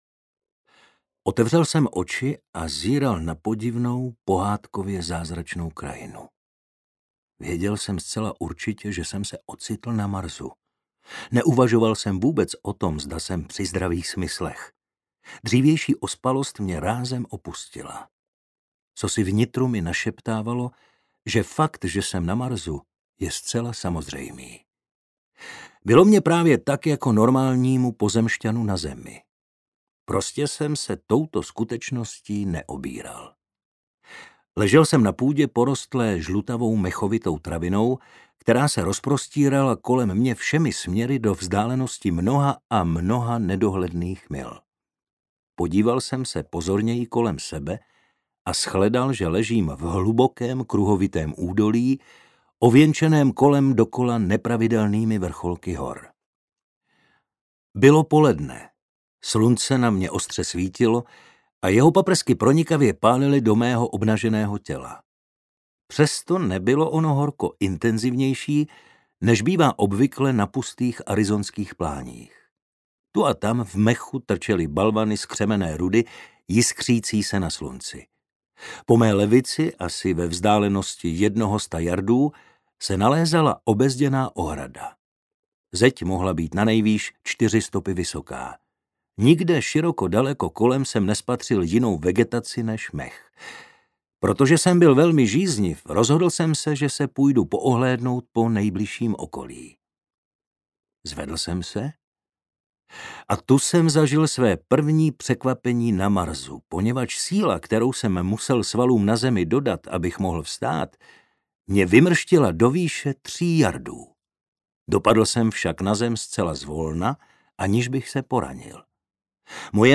Princezna z Marsu audiokniha
Ukázka z knihy
| Vyrobilo studio Soundguru.